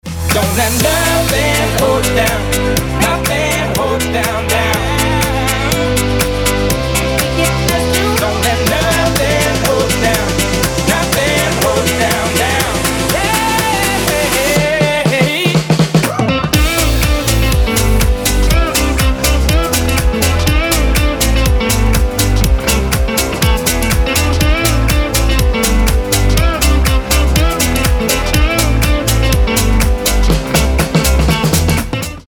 • Качество: 320, Stereo
ритмичные
мужской вокал
мелодичные
веселые
заводные
dance
спокойные
club